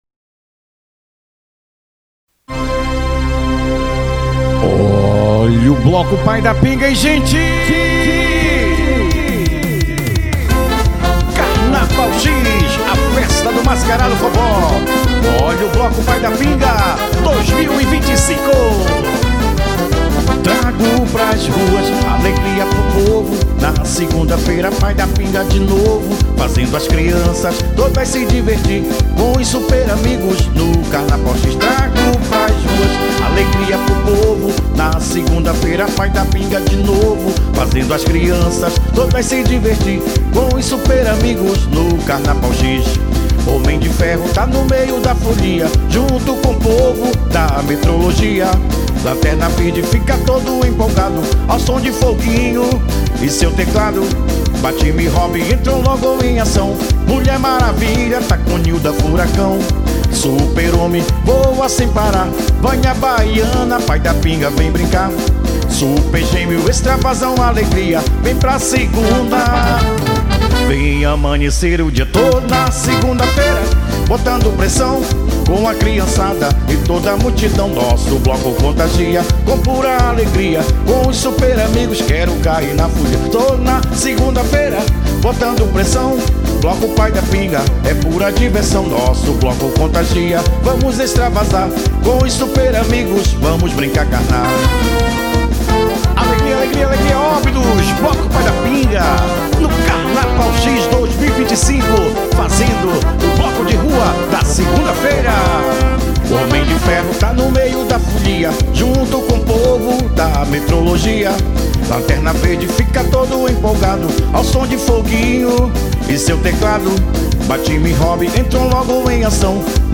guitarra
Teclado.
Sax